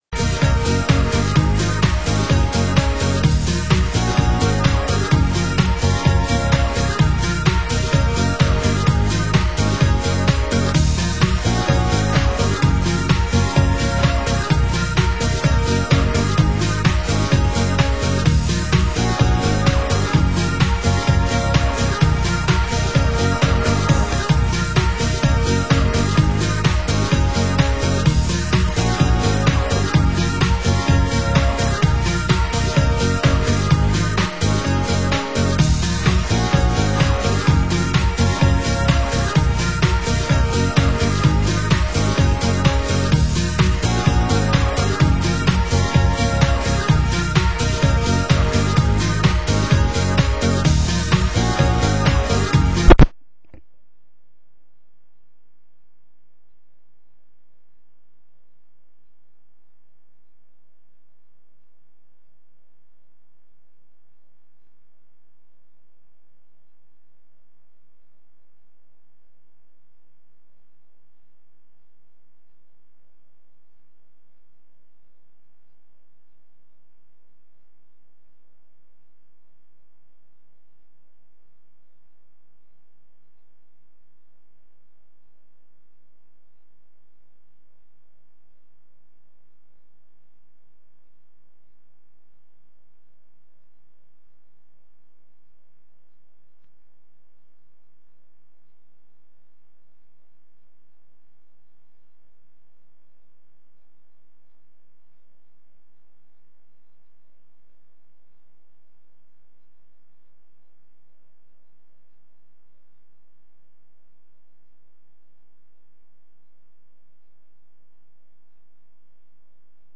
Genre: UK House
vocal
dub